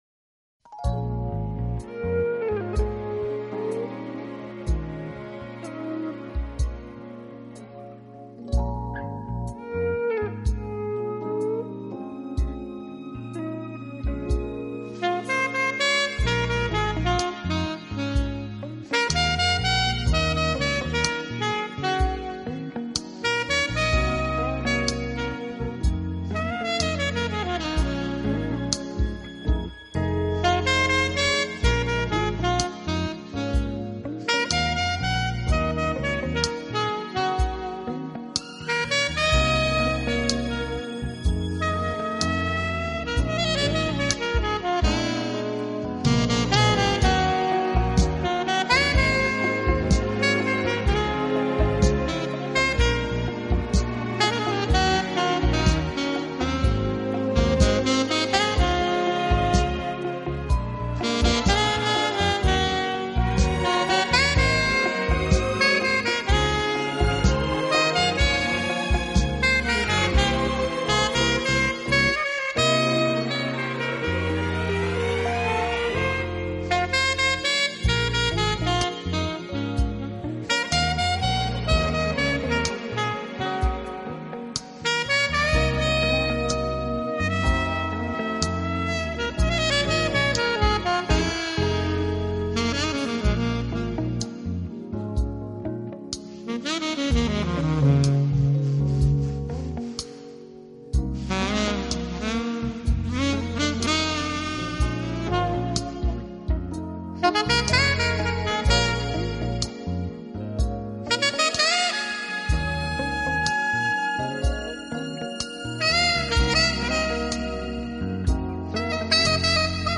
演奏风格浪漫醇和，精简的乐器以及巧妙的
音乐构思，优美迷人的旋律和带有蓝调色彩的演奏底蕴，留给乐迷无限的想象空间。
Sax (Alto), Sax (Soprano)
Synthesizer, Piano
Percussion, String Section and others